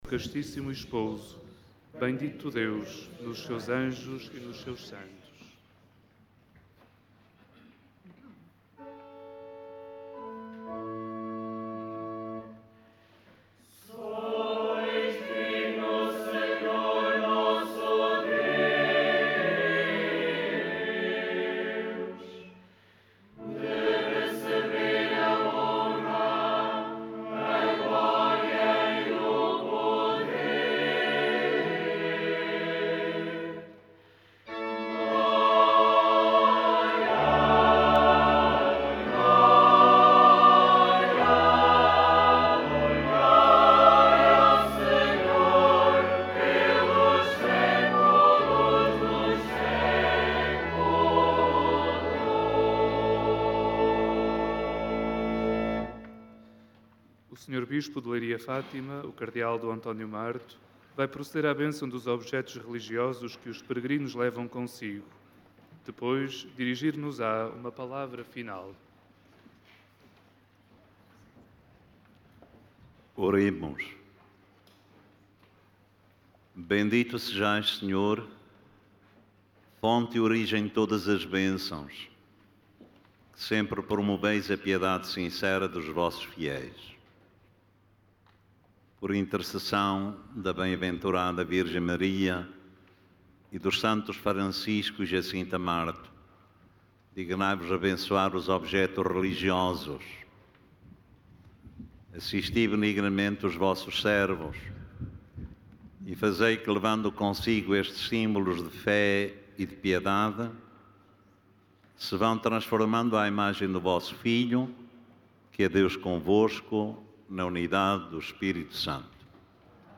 “É um gesto e um sinal muito belo de solidariedade e de coragem que honra a ação da GNR”, enfatizou o prelado no final da missa que encerrou a Peregrinação Internacional Aniversária de agosto, na qual participaram peregrinos da Peregrinação Nacional dos Migrantes.
Na alocução final, o prelado diocesano de Leiria-Fátima aludiu ainda à situação laboral que atravessa o país, marcada pela greve dos camionistas de matérias perigosas, agradecendo a todos os peregrinos presentes por terem vindo a Fátima.
Saudação final D. António Marto agosto 2019.MP3